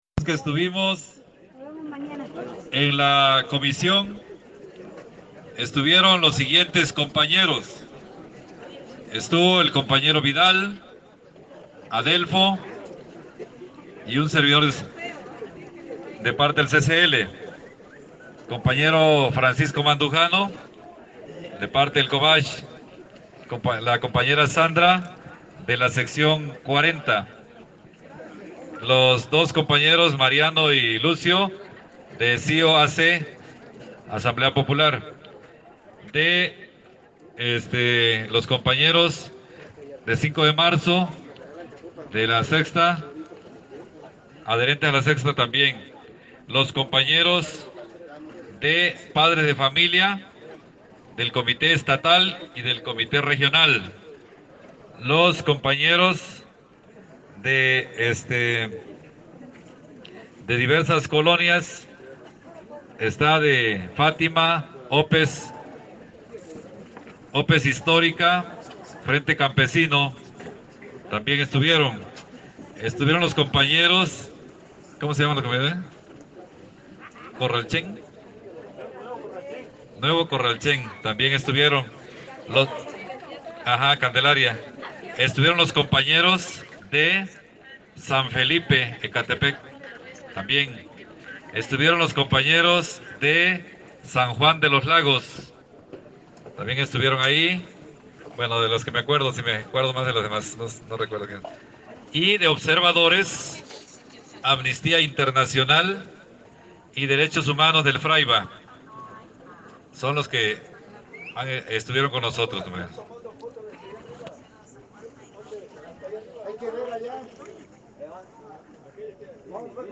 Este es el audio donde la comisión informa de los resultados de la mesa: